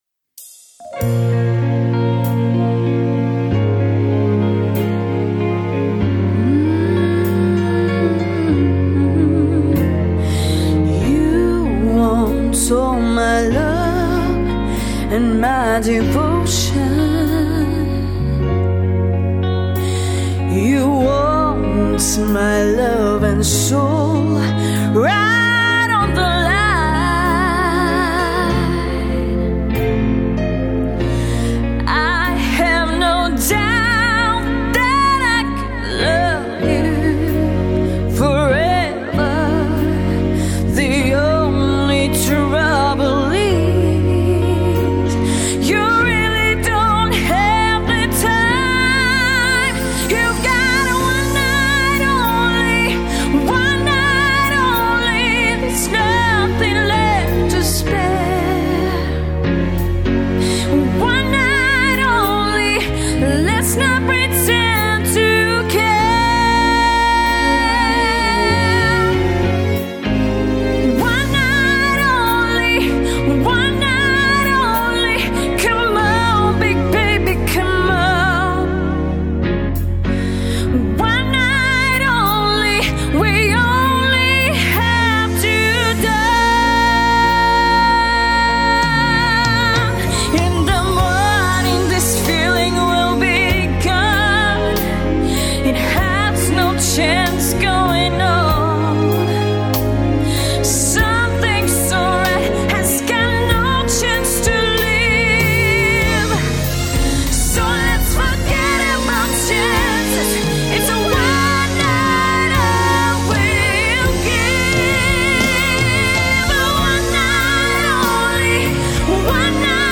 Сопрано Меццо-сопрано
Специальность: Эстрадно-джазовое пение